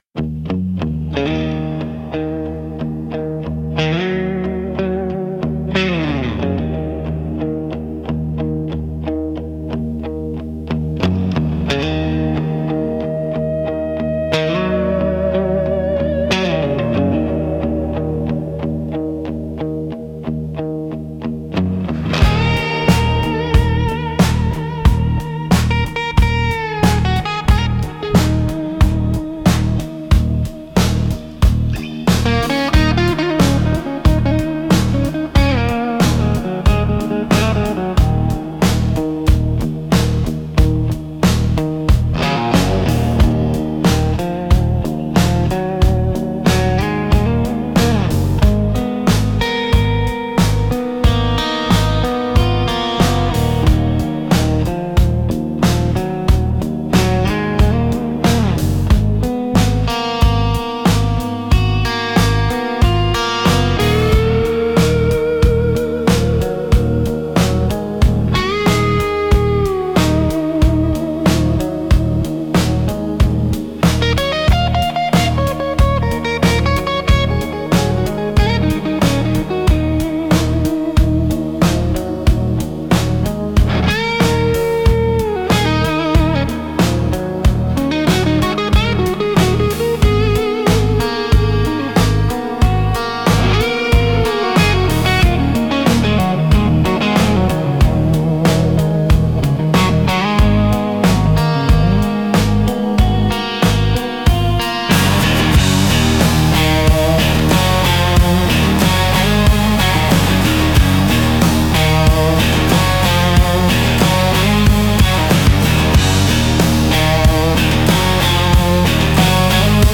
Instrumental - Motel Room Resonance 3.49